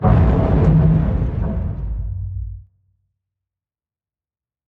Sounds / Damage / StructureBlunt5.ogg
StructureBlunt5.ogg